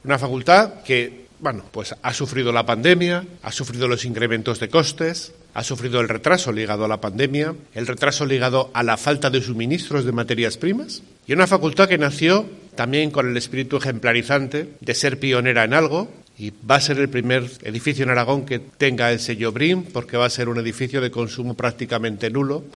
El rector de UNIZAR, José Antonio Mayoral, sobre la nueva Facultad de Filosofía y Letras de Zaragoza